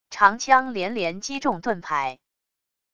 长枪连连击中盾牌wav音频